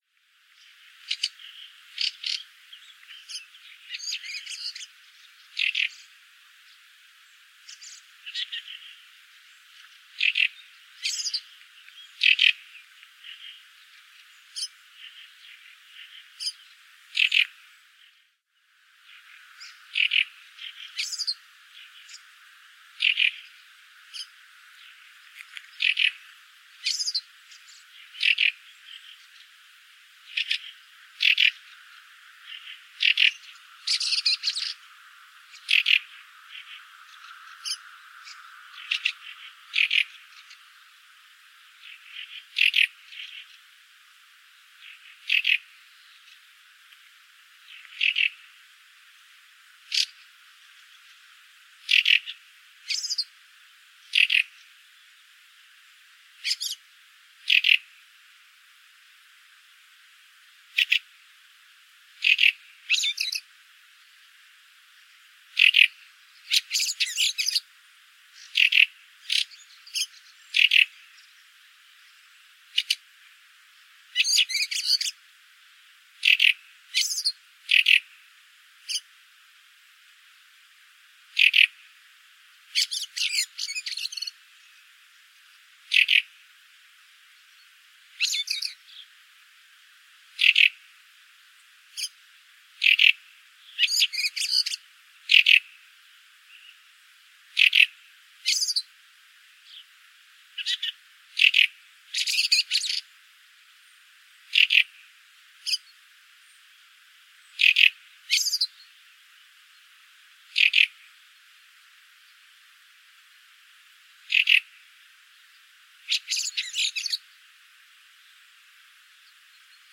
Wacholderdrossel
Turdus pilaris
Vogel